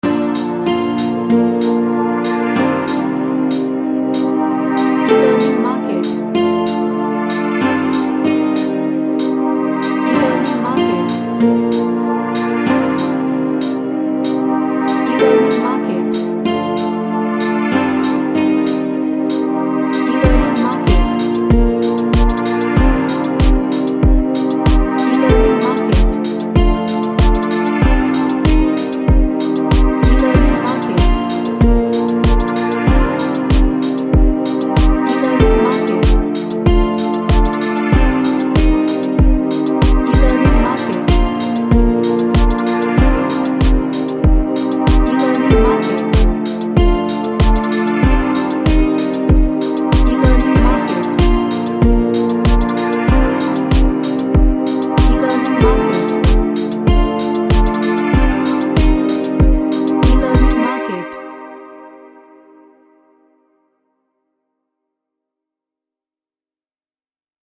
A Relaxing ambient track with pads.
Relaxation / Meditation